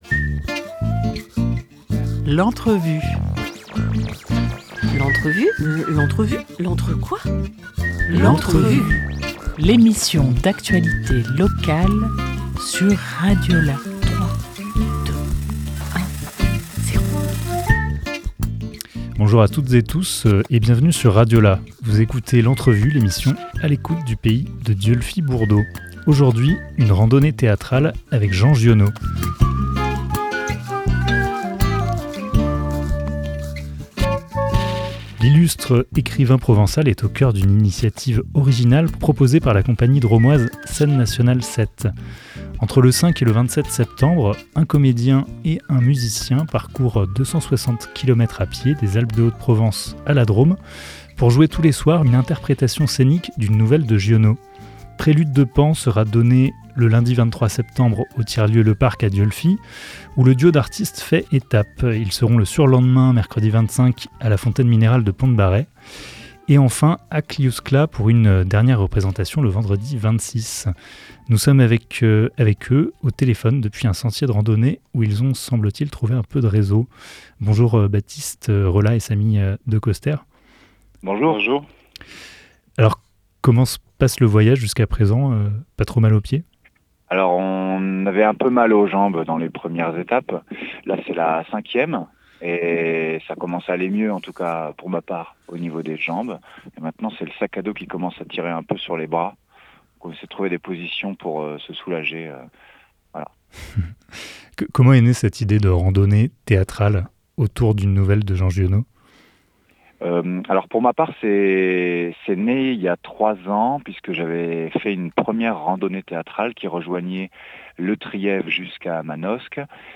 10 septembre 2024 18:01 | Interview
Entretien téléphonique depuis un sentier de randonnée.